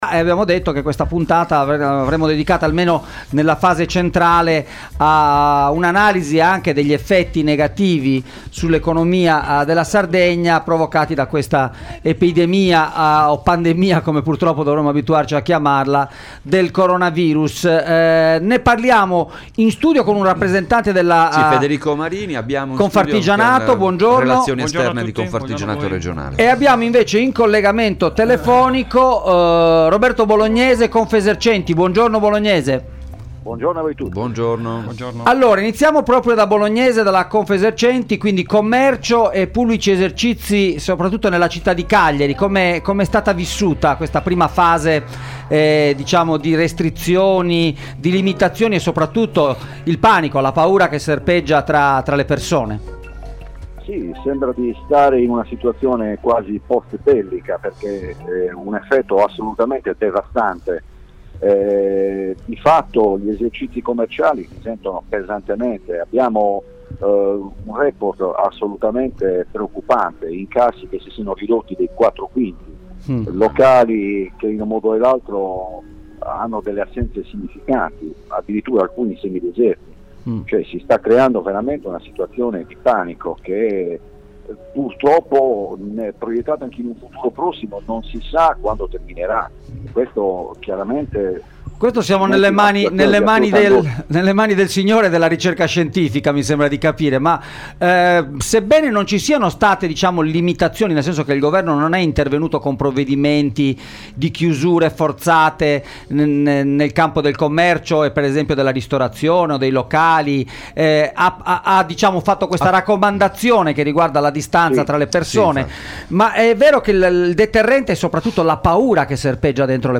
ai microfoni di Extralive mattina